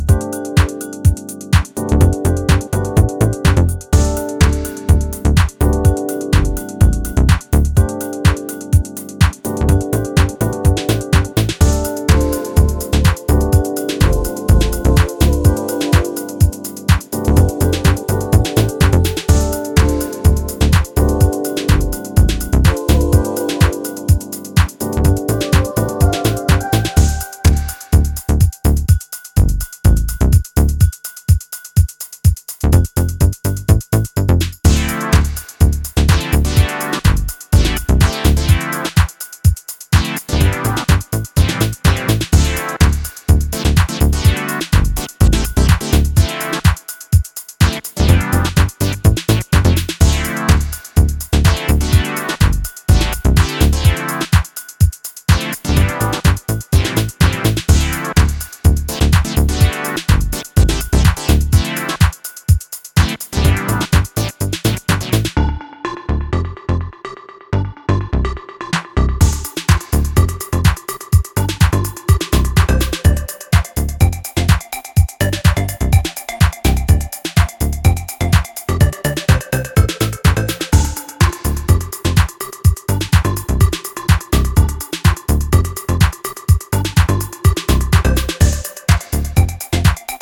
quickly turning into a rhythmic pattern